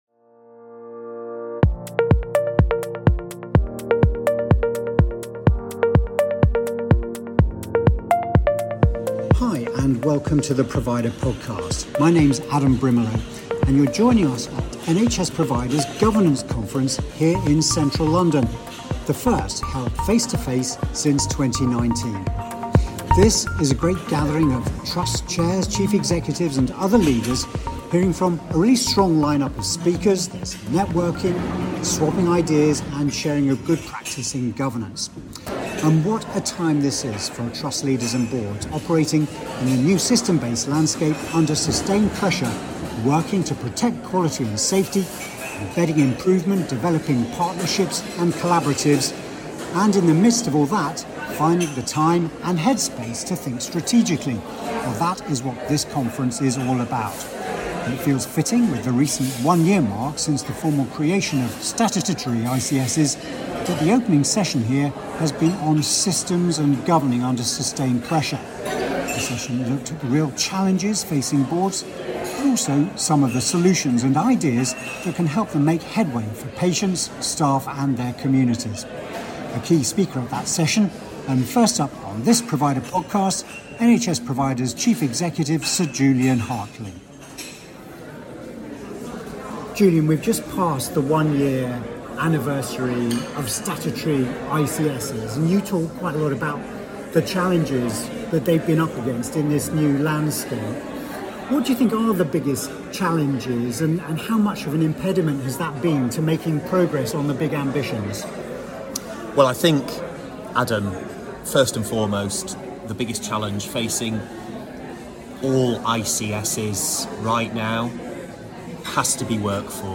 Recorded live at our Governance 2023 conference